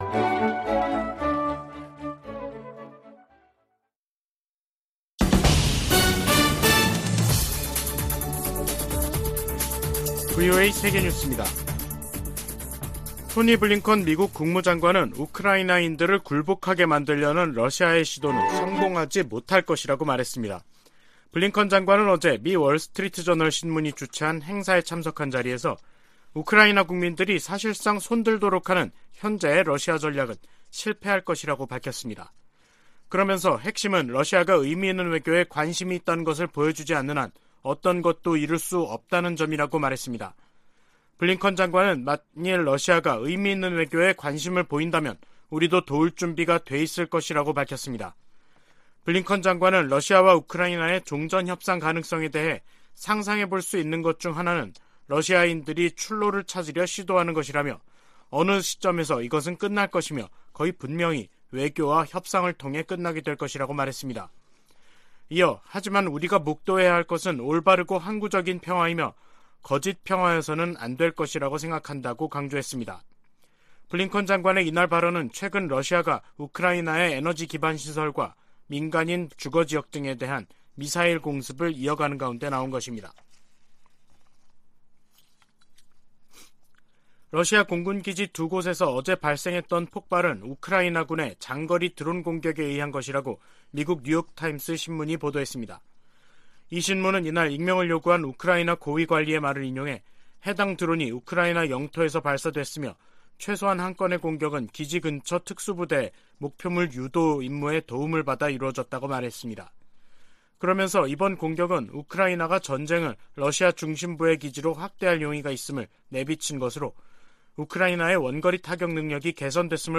VOA 한국어 간판 뉴스 프로그램 '뉴스 투데이', 2022년 12월 6일 3부 방송입니다. 북한 김정은 정권이 미군과 한국 군의 통상적인 훈련을 구실로 이틀 연속 9.19 남북 군사합의를 위반하며 해상완충구역을 향해 포 사격을 가했습니다. 중국이 미중 정상회담 이후에도 여전히 북한 문제와 관련해 바람직한 역할을 하지 않고 있다고 백악관 고위 관리가 지적했습니다.